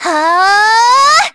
Isaiah-Vox_Casting1_kr_b.wav